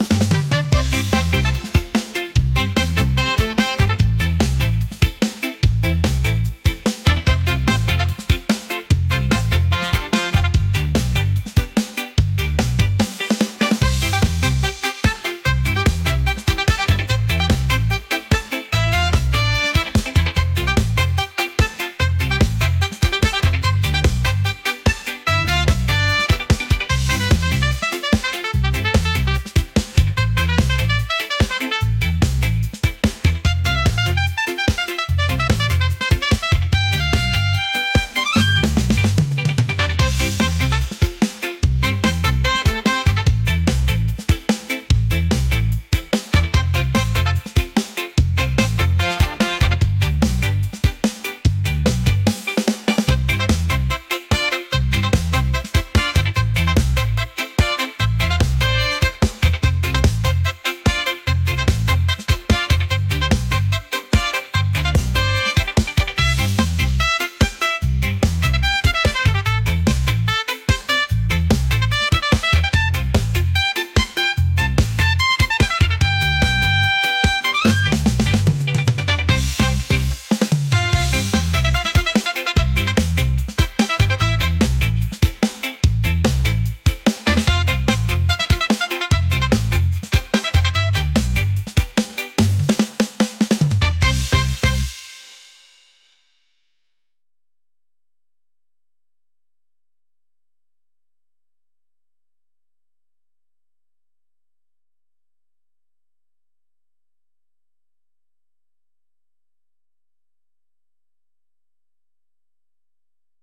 energetic | upbeat | reggae